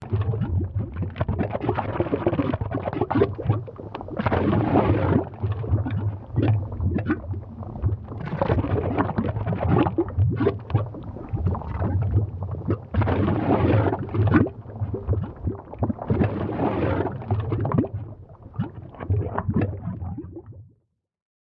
Lava Bubbles
Lava Bubbles is a free sfx sound effect available for download in MP3 format.
yt_aEhwwn9gdEY_lava_bubbles.mp3